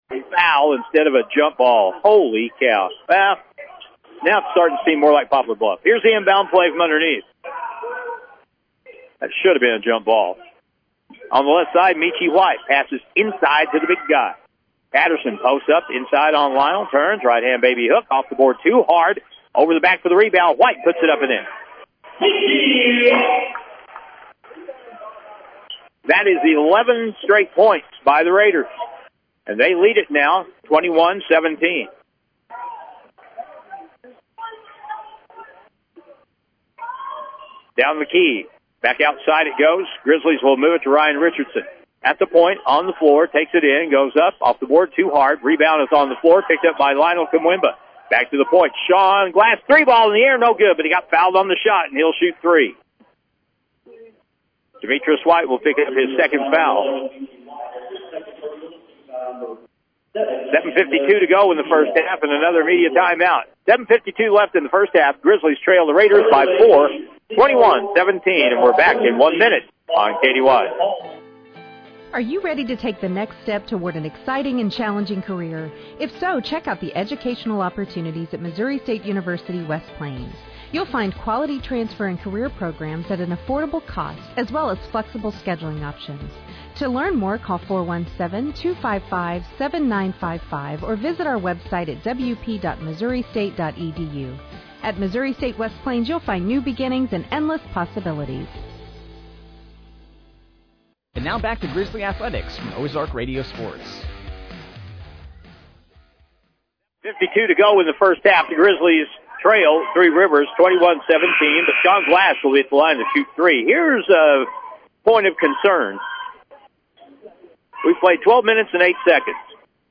Game Audio Below:
Missouri-State-West-Plains-Grizzlies-vs.-Three-Rivers-Raiders-1-11-25.mp3